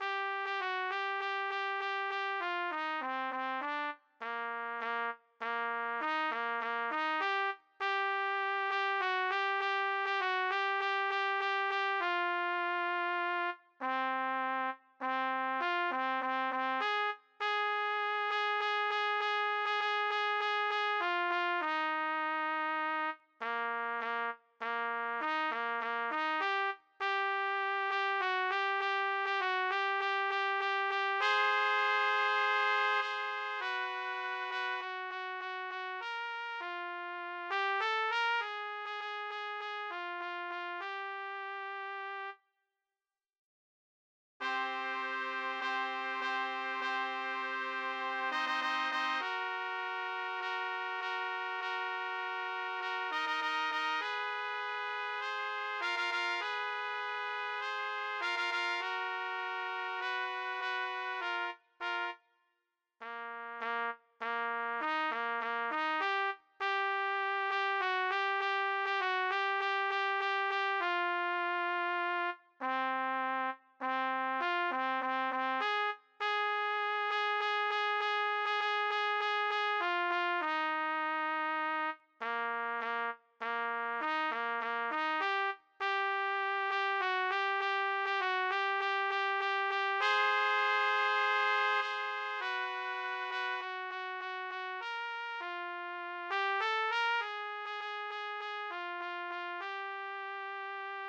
B=Harmony-for intermediate players